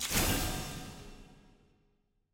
sfx-eog-ui-diamond-burst.ogg